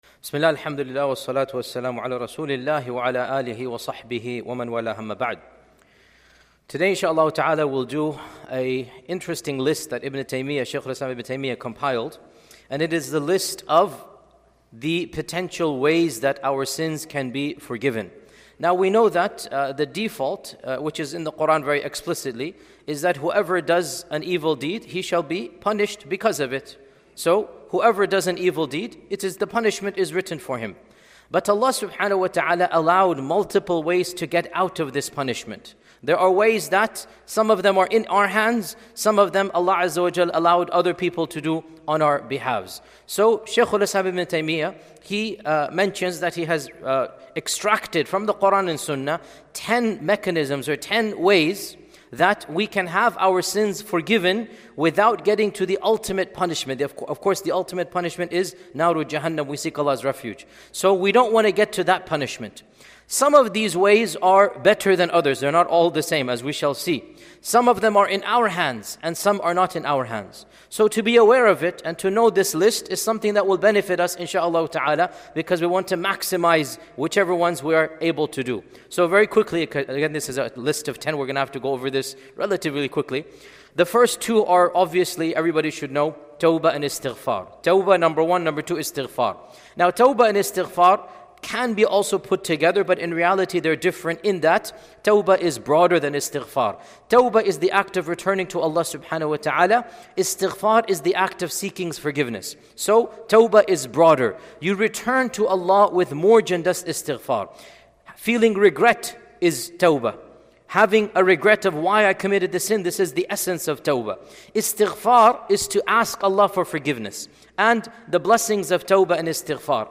10 Ways to Have Your Sins Forgiven ｜ Fajr Khatira ｜ Shaykh Dr. Yasir Qadhi.mp3